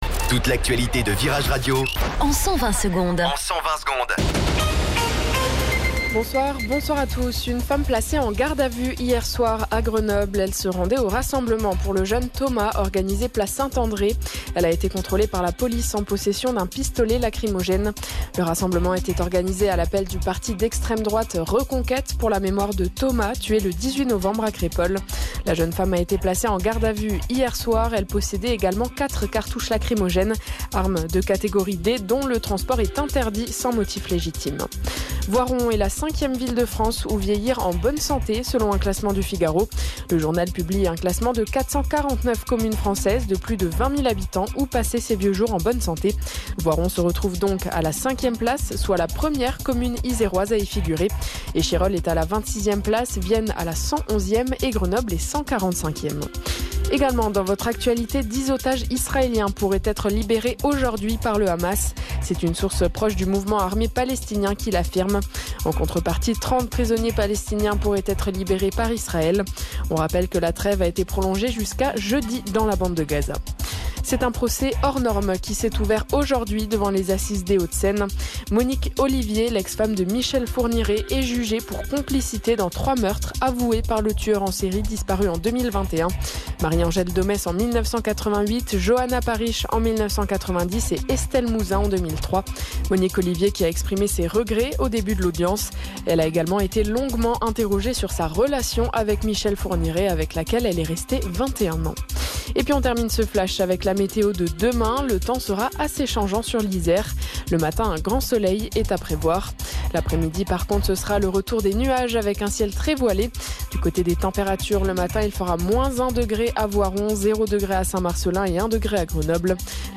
Flash Info